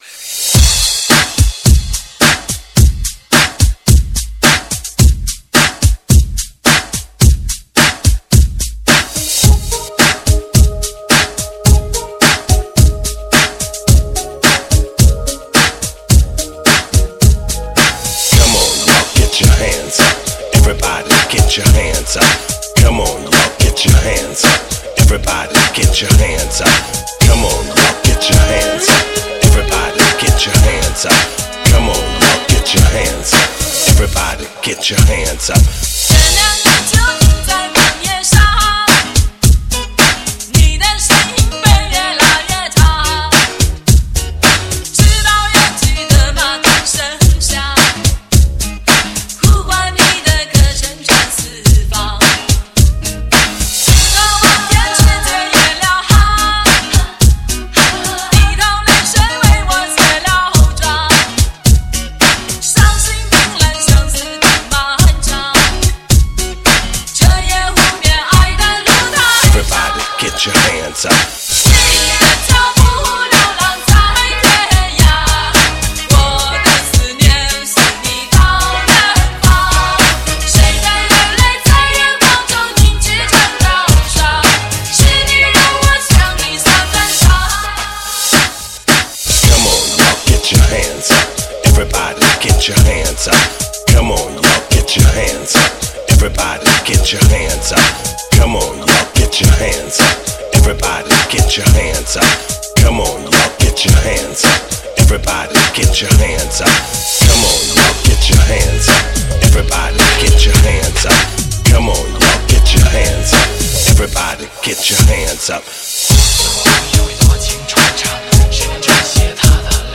最爆的节奏 引领潮流新感觉
最嗨的舞曲 最狂的音乐
令你一路慢摇到底 绝对不容错过
DJ极品音潮全球迪厅必备